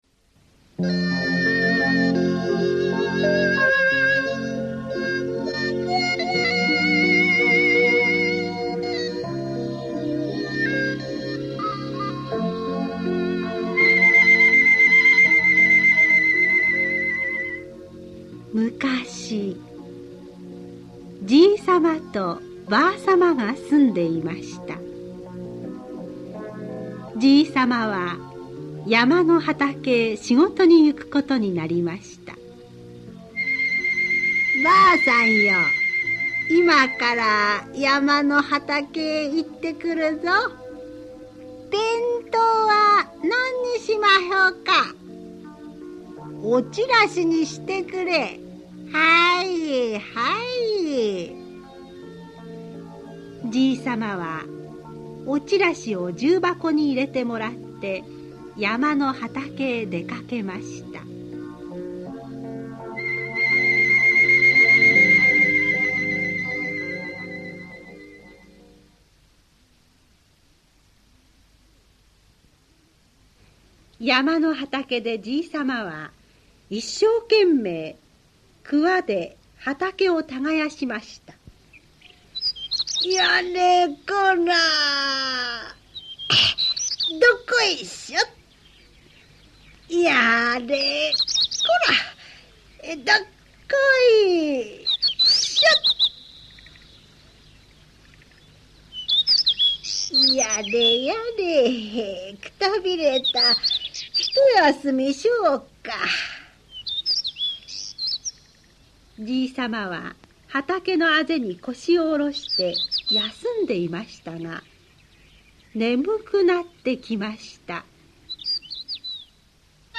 [オーディオブック] しろ仏さま